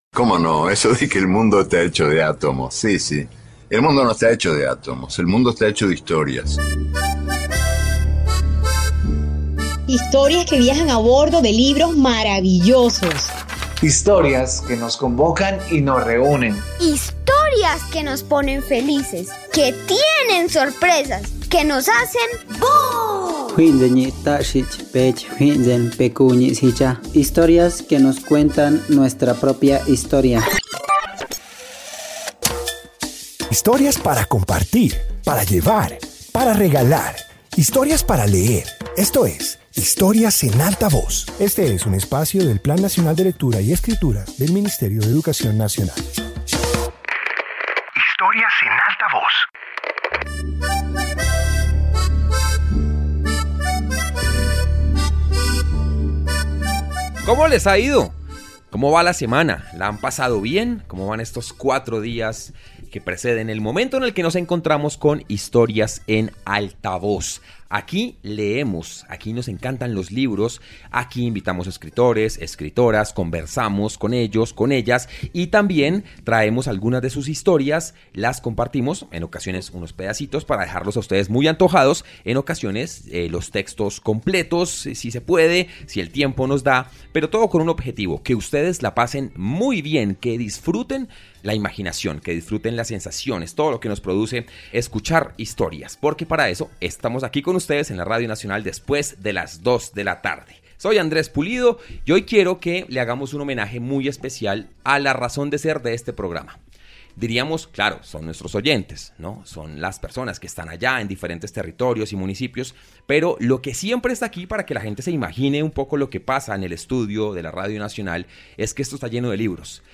Introducción Este episodio de radio reúne narraciones sobre obras, autores y memorias relacionadas con el universo del libro. Presenta tesoros literarios que han acompañado a distintos lectores a lo largo del tiempo.